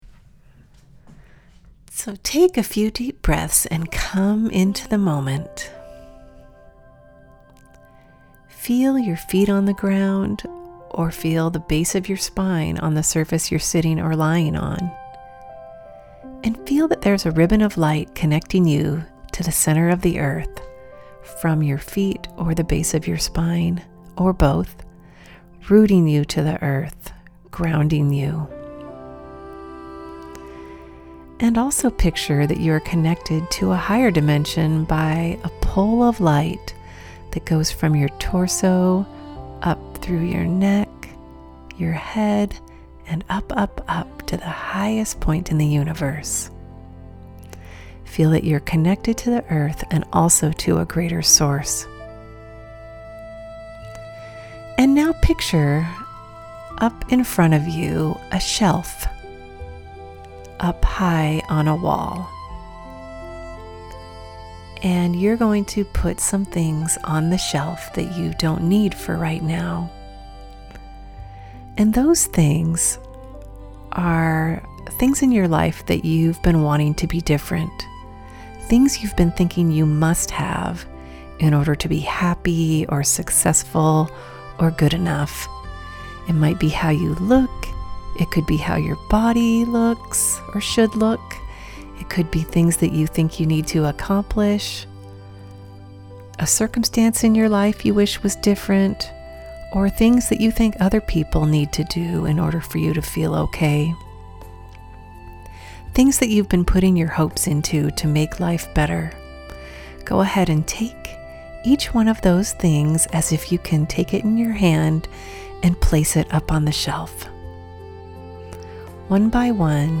This beautiful meditation allows you to release the burdens you’re carrying, accept your current reality, and open to an expanded sense of oneness that you can carry with you.